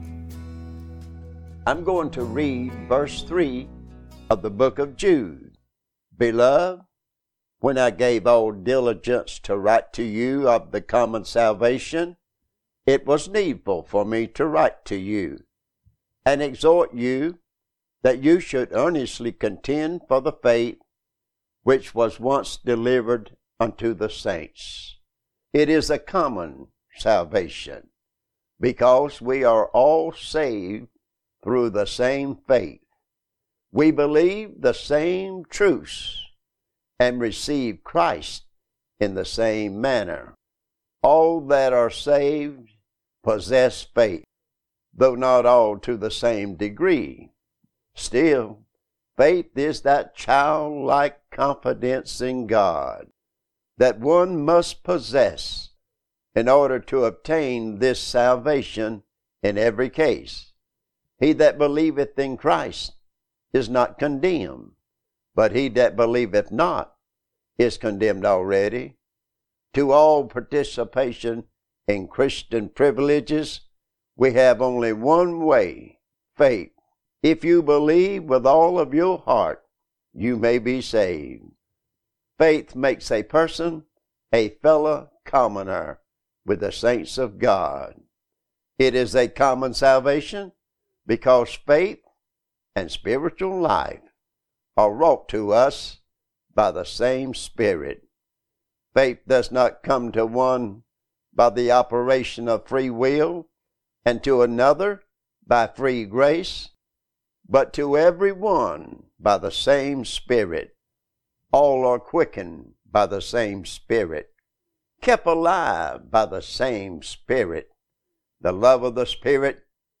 Last of five lessons